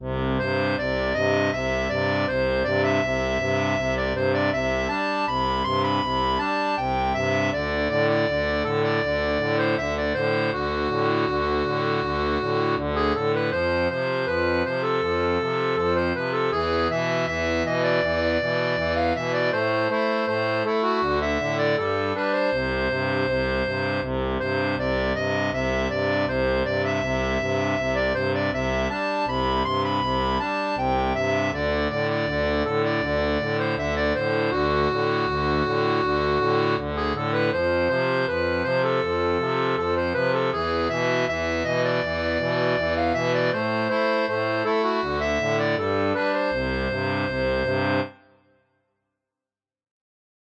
Type d'accordéon
Chanson française